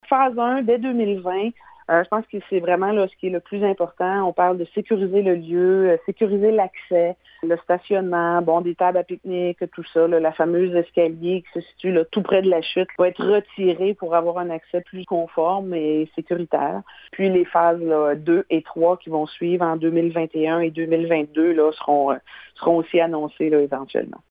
Son aménagement sera réalisé en 3 phases au cours des prochaines années, comme le mentionne la mairesse de Percé, Cathy Poirier :